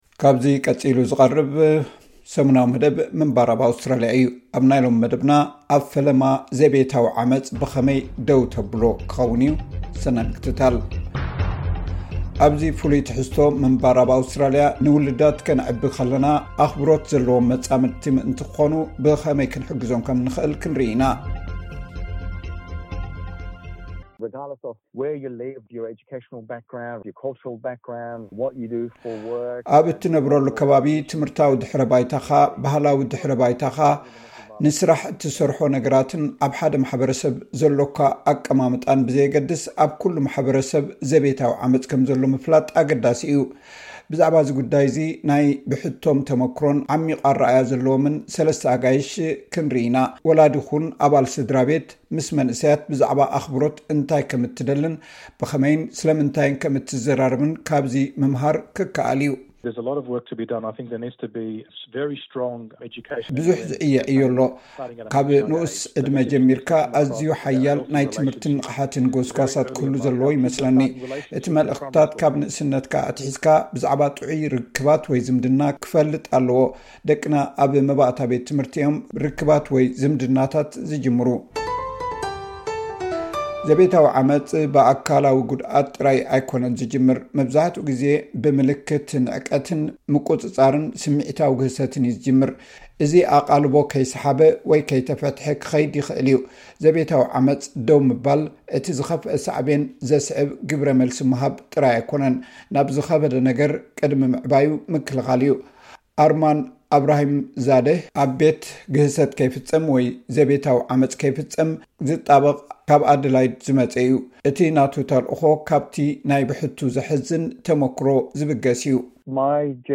ኣብዚ ፍሉይ ትሕዝቶ ምንባር ኣብ ኣውስትራሊያ ፡ ንውሉዳትን ከነዕብዮም ከለና ፡ ኣኽብሮት ዘለዎም መጻምድቲ ምእንቲ ክዀኑ ብኸመይ ክንሕግዞም ከም እንኽእል ኽንርእይ ኢና ። ብዛዕባ እዚ ጕዳይ እዚ ናይ ብሕቶም ተመኩሮን ዓሚቝን ኣረኣእያ ዘለዎም ሰለስተ ኣጋይሽ ክትረክብ ኢኻ ። ወላዲ ኩን ኣባል ስድራ ቤት ፡ ምስ መንእሰያት ብዛዕባ ኣኽብሮት እንታይ ከም እትደልን ብኸመይን ስለምንታይን ከም እትዘራረብን ካብዚ ምምሃር ይከኣል እዩ ።